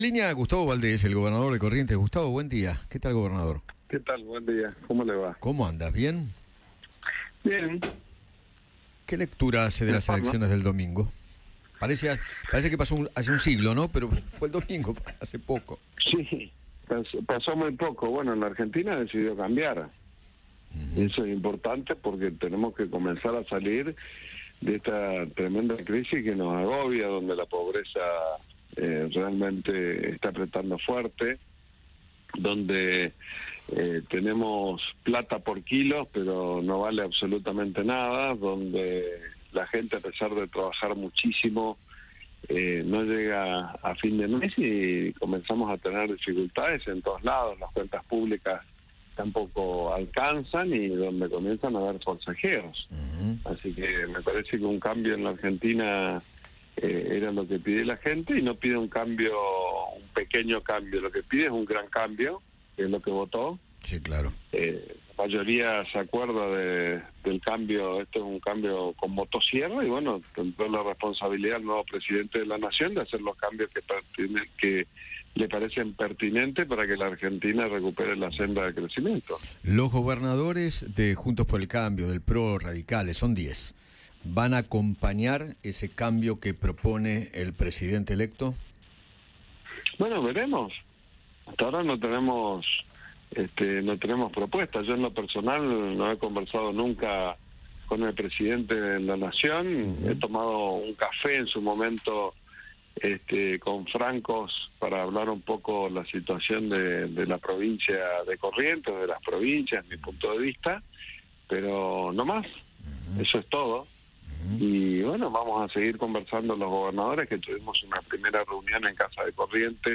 Gustavo Valdés, gobernador de Corrientes, dialogó con Eduardo Feinmann sobre el resultado del balotaje presidencial, que dio como ganador a Javier Milei.